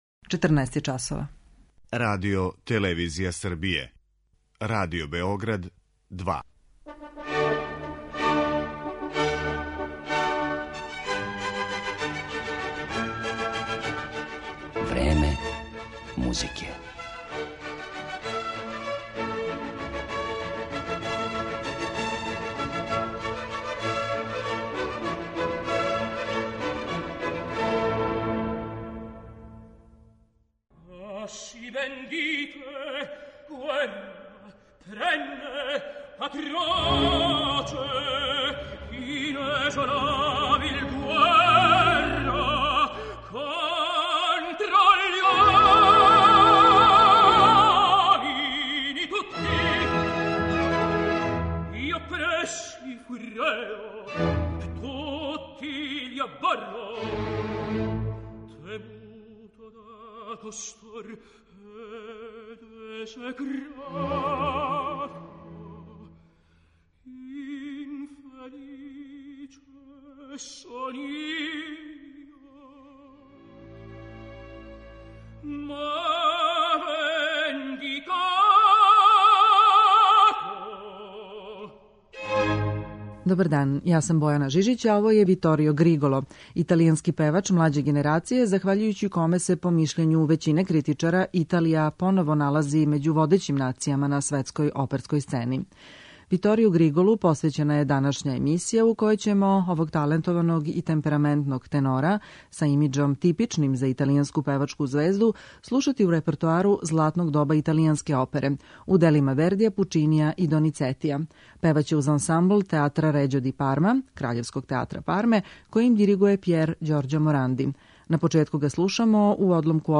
Виторио Григоло пева арије из Вердијевих, Пучинијевих и Доницетијевих опера
Као каризматично шармантног, темпераментог и свестрано обдареног, описују критичари певачку звезду, италијанског лирског тенора Виторија Григола. Он је својим моћним и колористички богатим гласом, виртуозним и изражајним певањем и убедљивом сценском појавом, за кратко време освојио и оперску публику и стручњаке широм света.